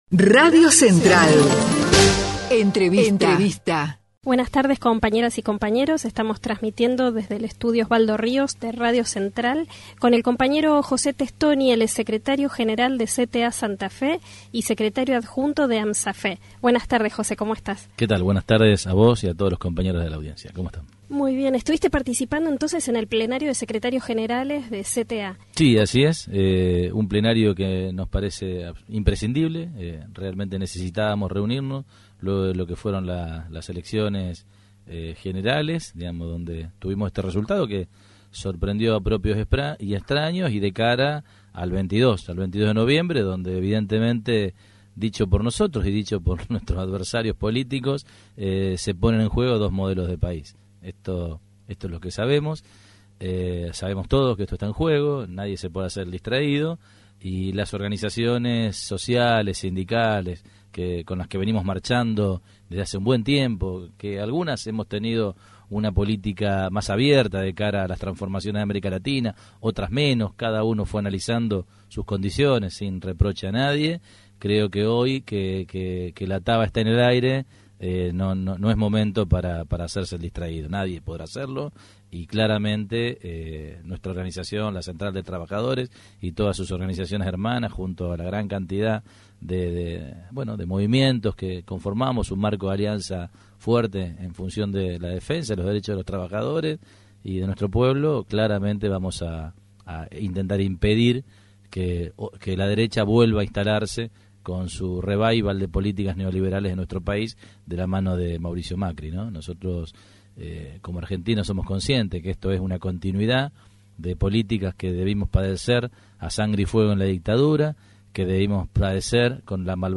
Plenario Nacional de Secretarios Generales de la Central de Trabajadores de la Argentina